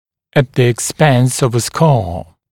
[ət ðə ɪk’spens əv ə skɑː] [ek-][эт зэ ик’спэнс ов э ска:] [эк-]ценой наличия шрама